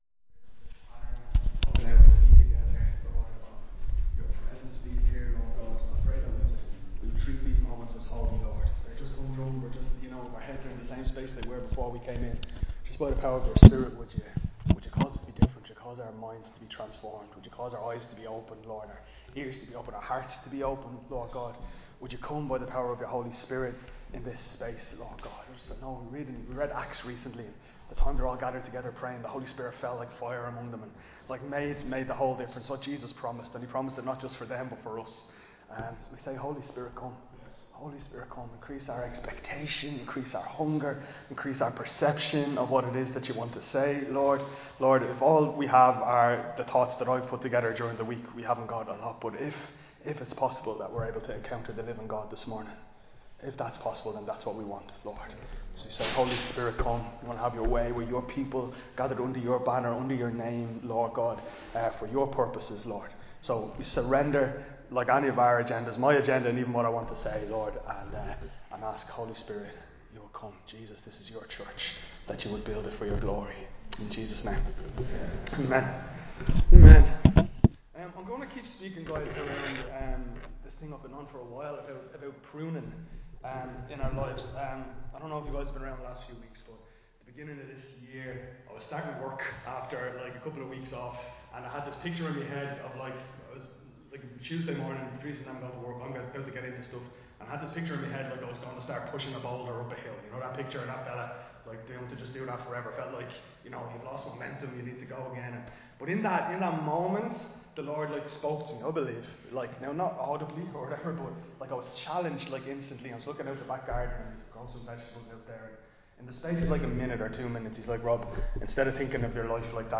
Recorded live in Liberty Church on 16 March 2025